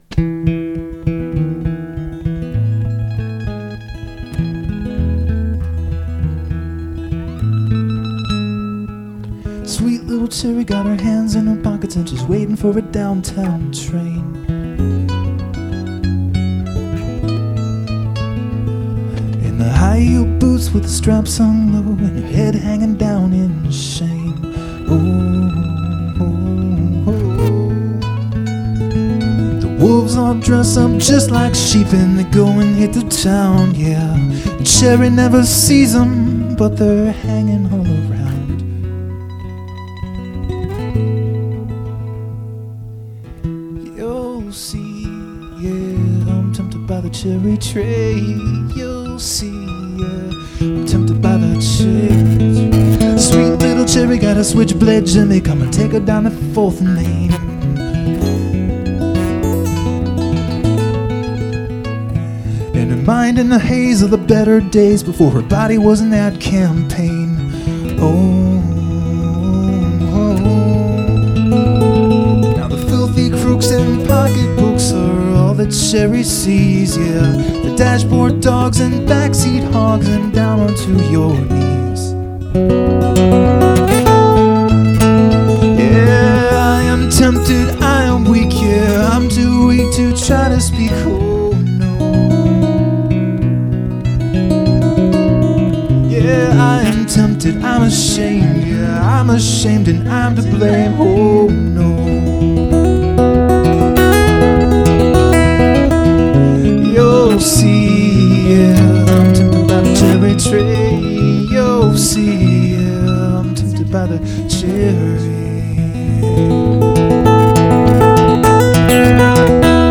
Here are some random MP3s from my live shows: